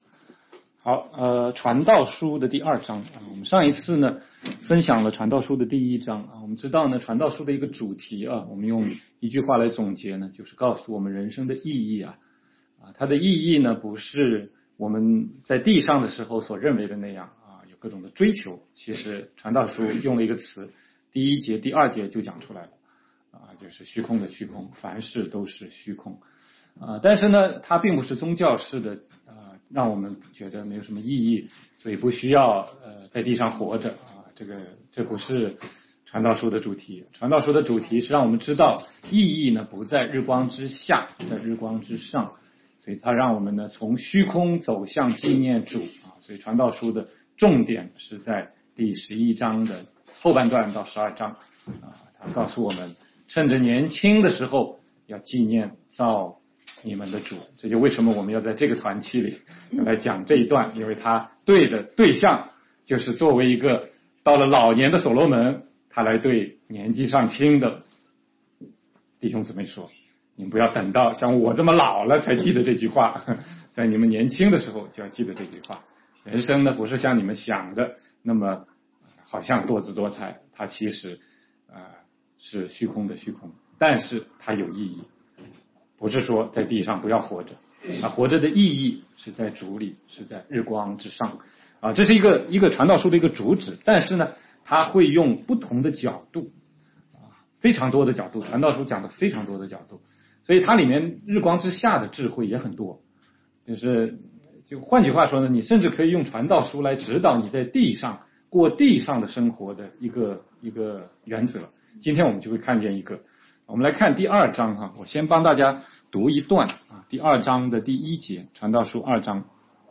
16街讲道录音 - 从传道书看人生的意义：从虚空到记念造你的主（2）